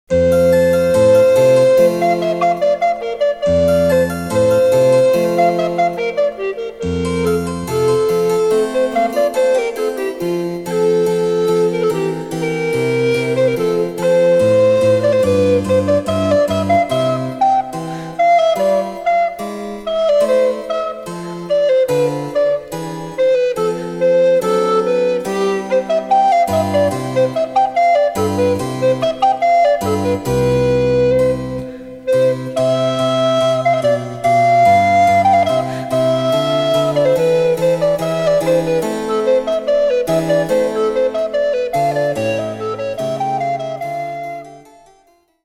デジタルサンプリング音源使用
・伴奏はモダンピッチのみ。